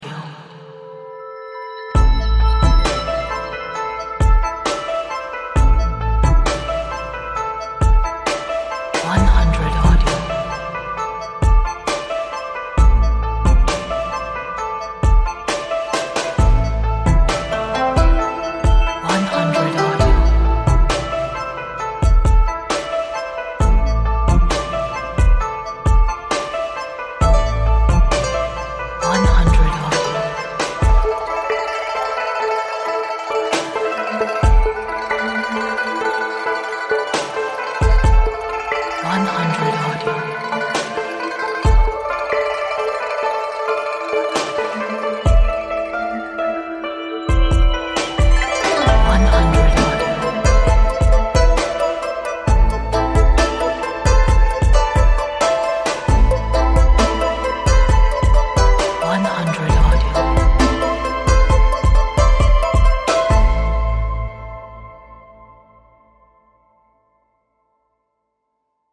琵琶，杨琴，古筝，结合现代电子与律动。在都市包裹下的弄堂，显得别有一番风味。